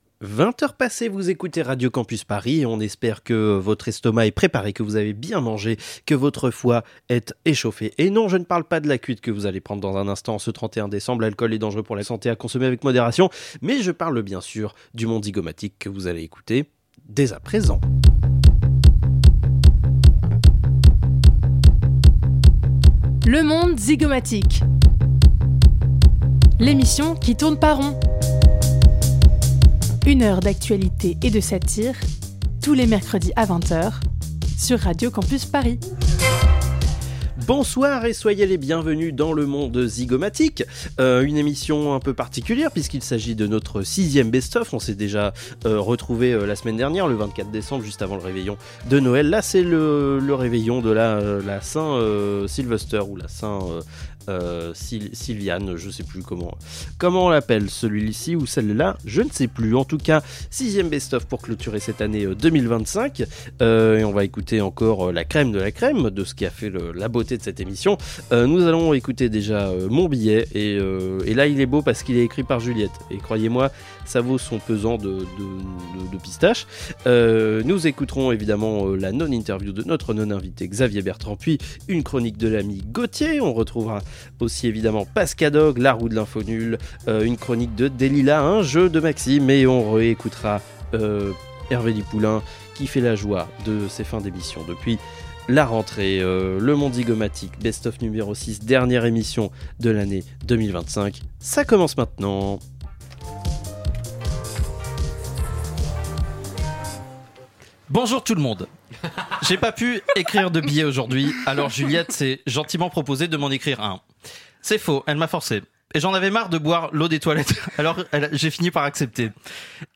Radio Campus Paris est la radio associative et locale des étudiants et des jeunes franciliens.